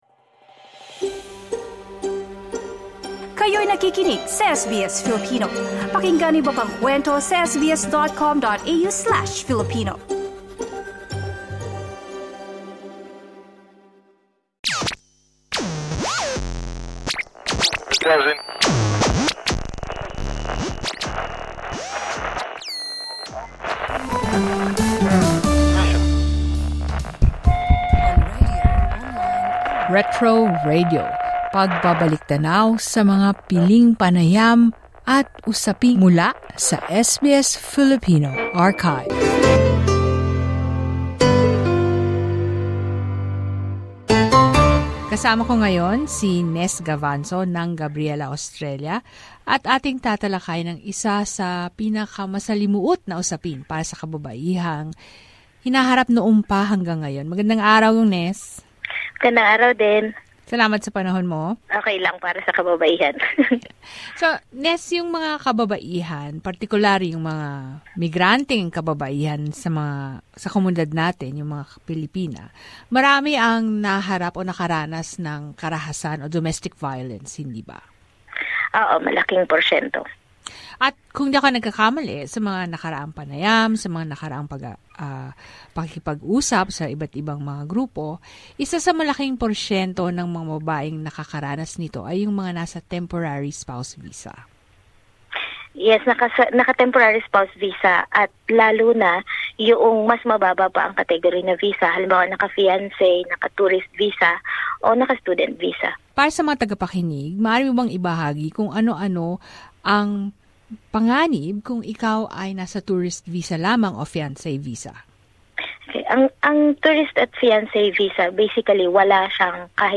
We look back at a 2017 Gabriela Australia interview calling for equal access to services and support for women in Australia who are victims of domestic violence.
Retro Radio: Interviews from the SBS Filipino archives.